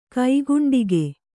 ♪ kaiguṇḍige